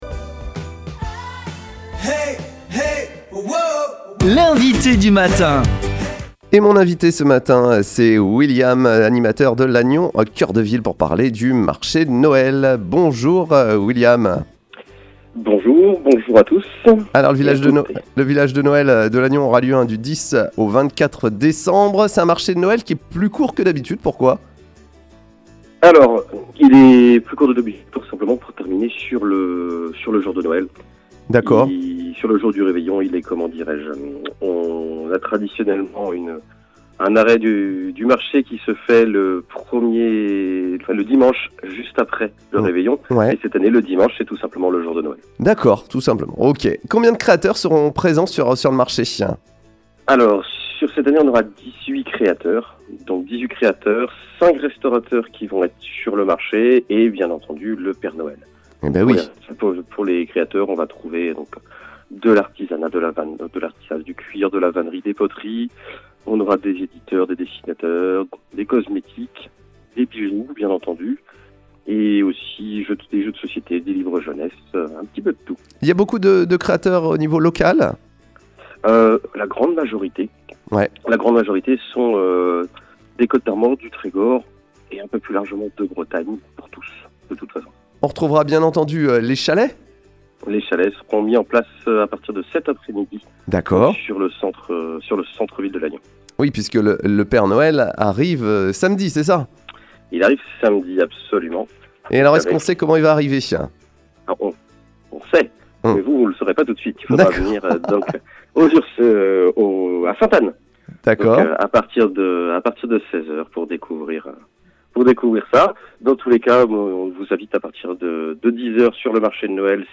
L'invité